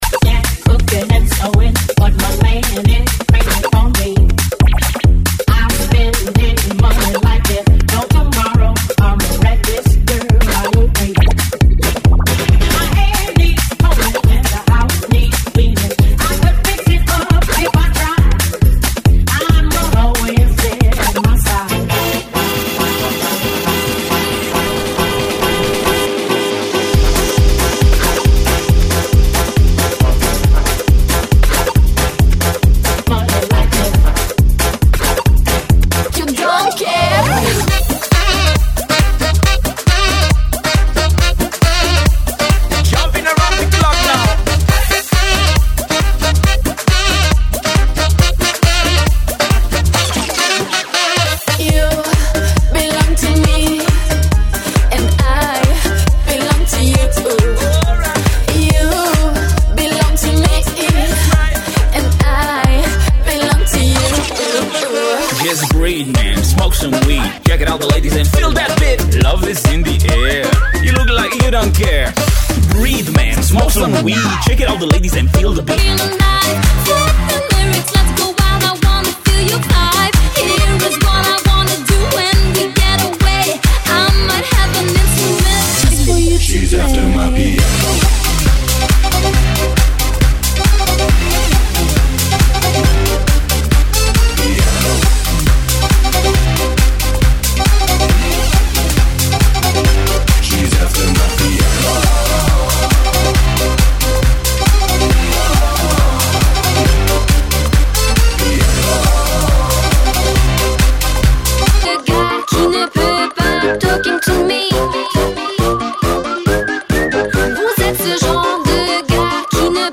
GENERO: REMIXES DEL MUNDO
MUNDO REMIX, ELECTRO, POP, REMIX,